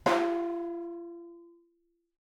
Percussion
vibraring_v1_rr2.wav